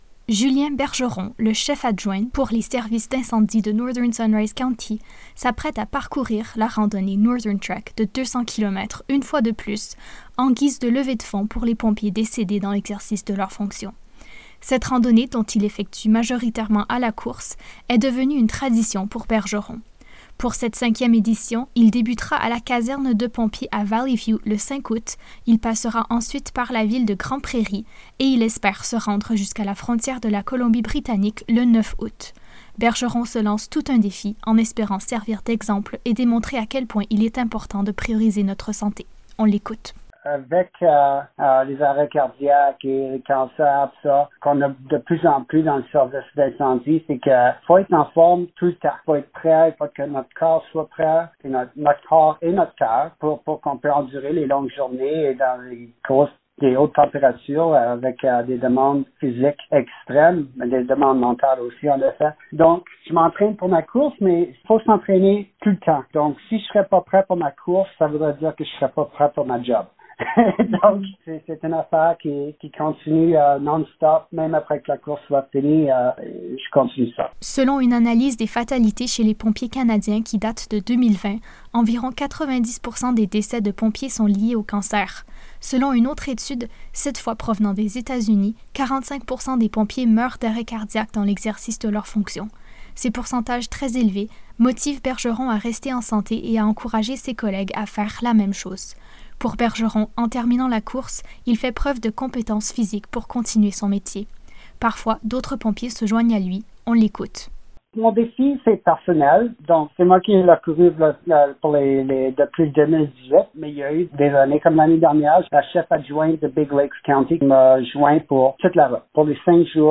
Pour en savoir davantage sur cette levée de fonds, écoutez le reportage :